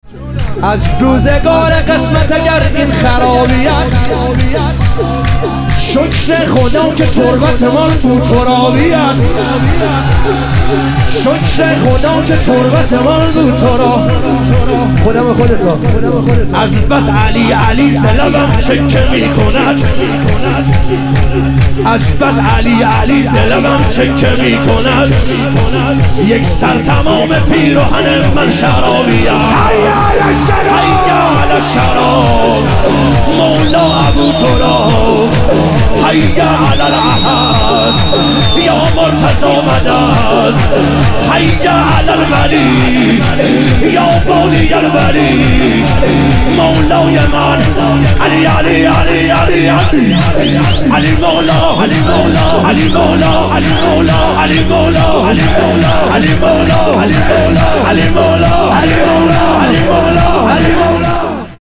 ظهور وجود مقدس حضرت عباس علیه السلام - شور